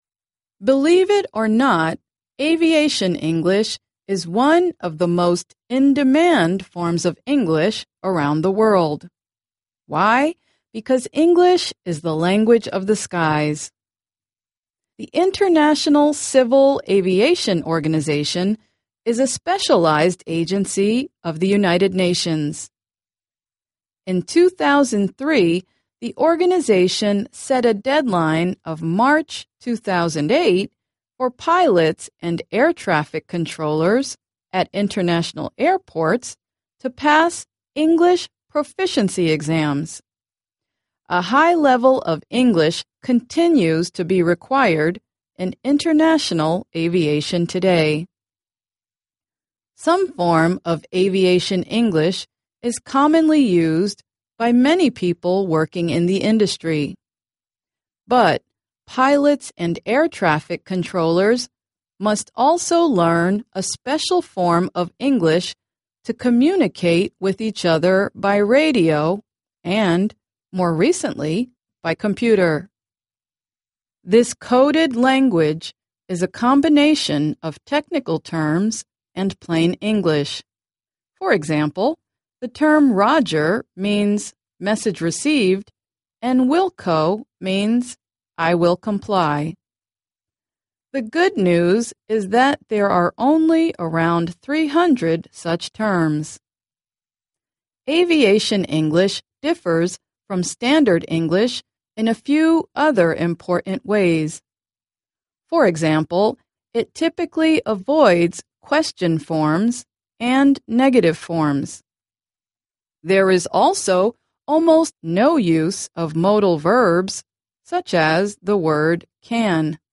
Audios en inglés con trascripción completa